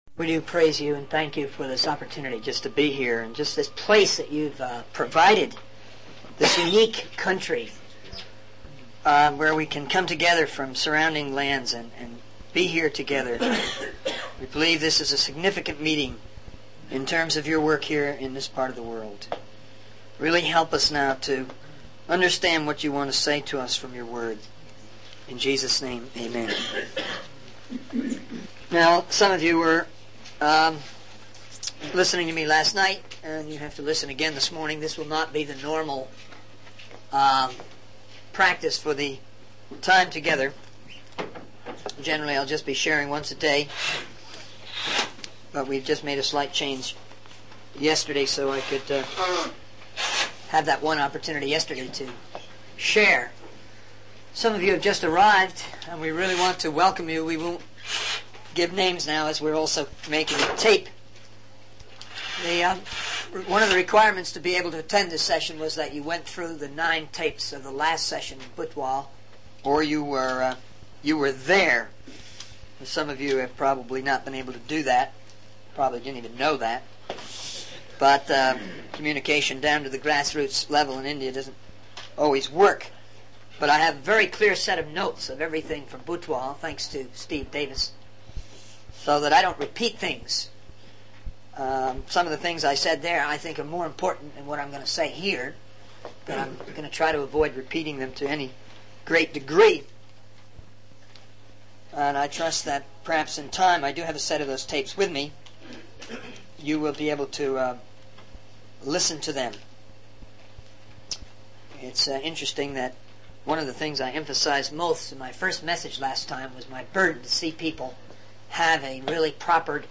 In this sermon, the speaker reflects on his own journey of transformation and acknowledges that he is still a work in progress. He emphasizes the importance of testing the spirits behind our dreams and desires, referring to the scripture in 1 John 4:1. The speaker also discusses the need for seeking counsel from multiple sources and peers to discern God's will.